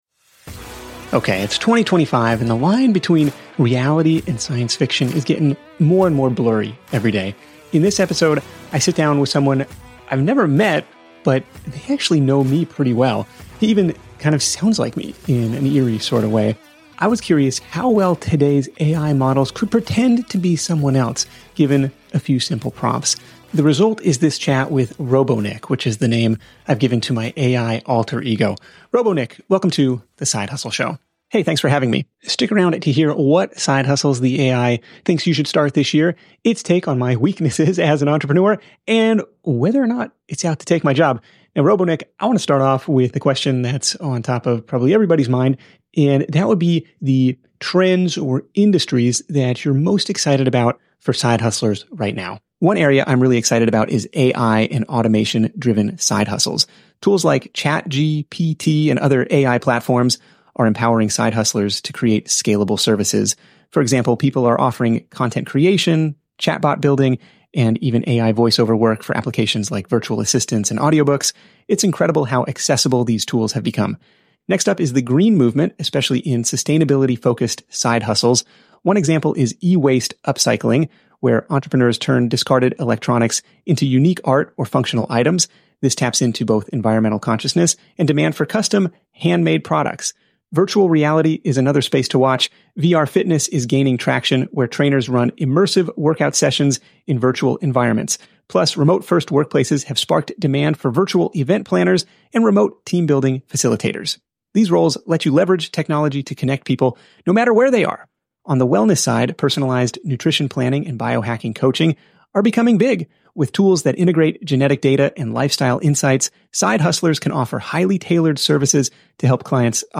He even kind of sounds like me in an eerie sort of way. I was curious how well today’s AI models could pretend to be someone else, given a few simple prompts.